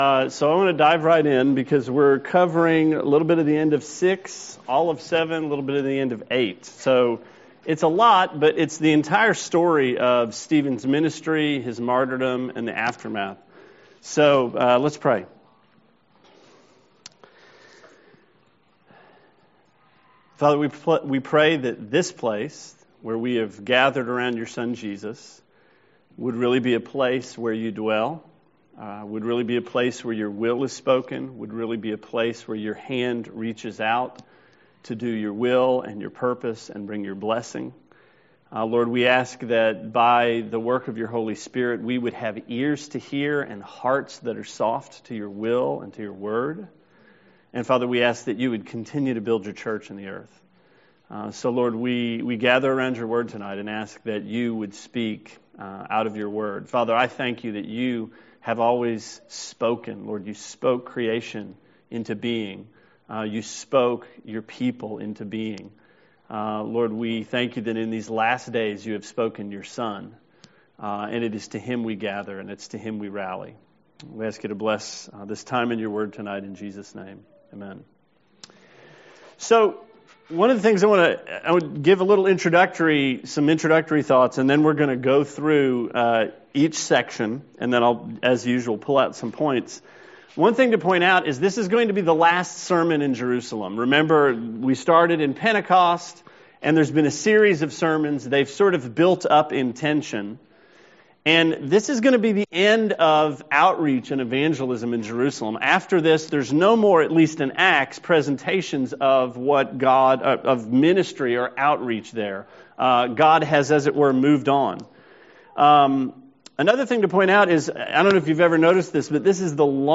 Sermon 5/6: Acts 7: Stephen